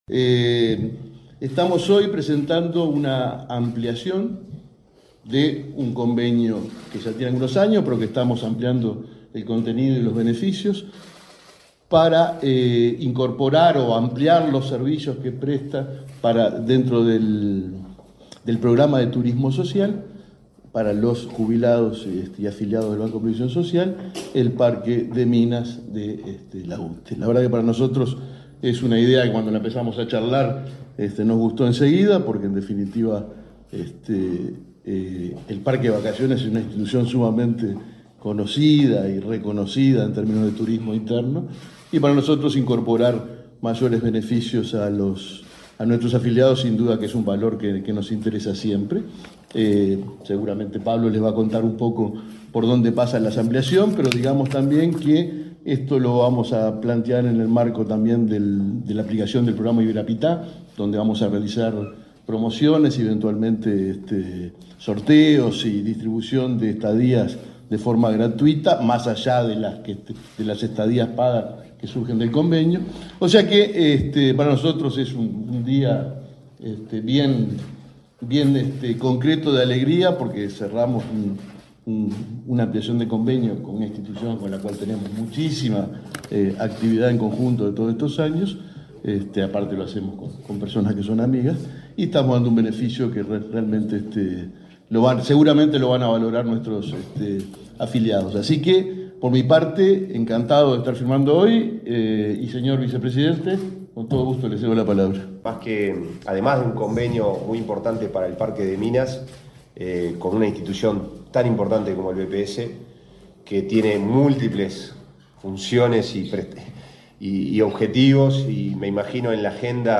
Palabras de autoridades en acto en BPS